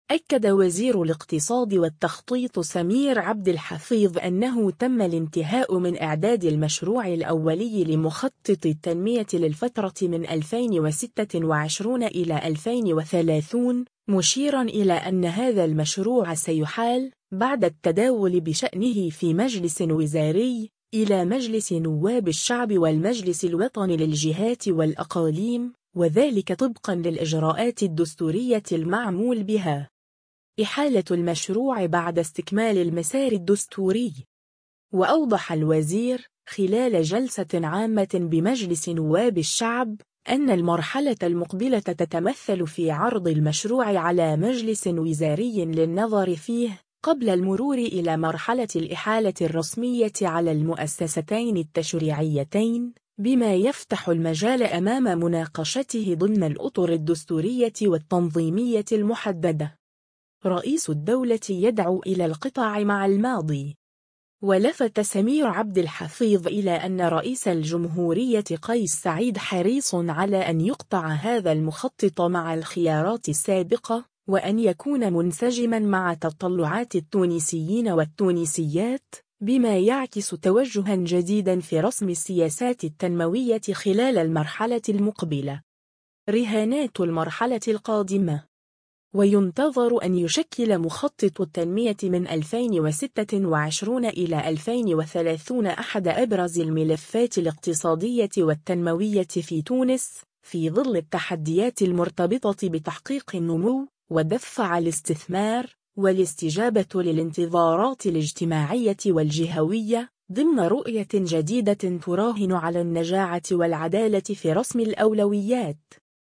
وأوضح الوزير، خلال جلسة عامة بمجلس نواب الشعب، أن المرحلة المقبلة تتمثل في عرض المشروع على مجلس وزاري للنظر فيه، قبل المرور إلى مرحلة الإحالة الرسمية على المؤسستين التشريعيتين، بما يفتح المجال أمام مناقشته ضمن الأطر الدستورية والتنظيمية المحددة.